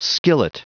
Prononciation du mot skillet en anglais (fichier audio)
Prononciation du mot : skillet